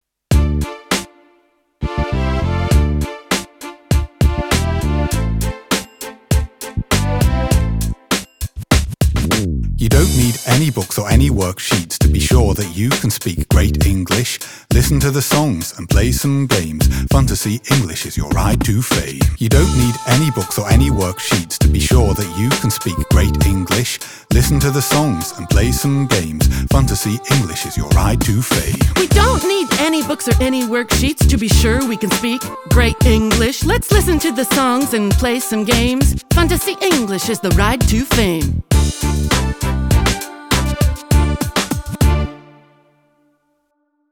Funtasy-English-engl-rap.mp3